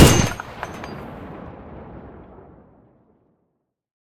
gun-turret-end-1.ogg